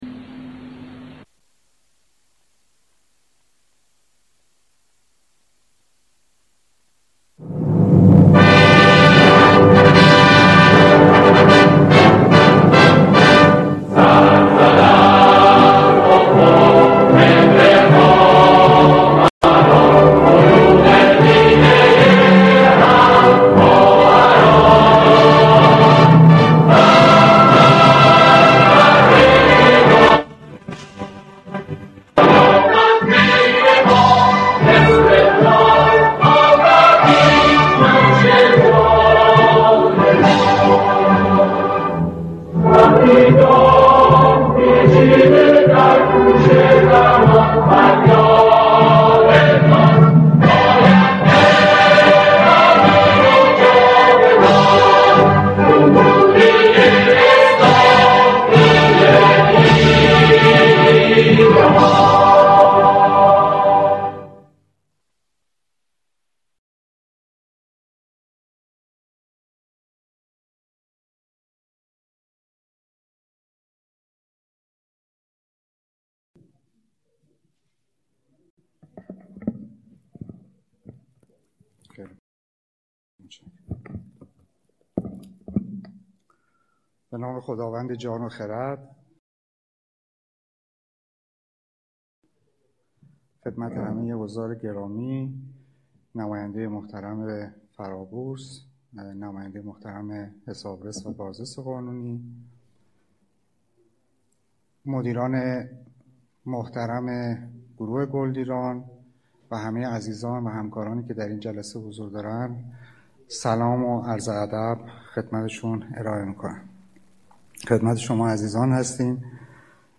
مجمع عمومی عادی بطور فوق العاده شرکت صنایع گلدیران - نماد: گلدیرا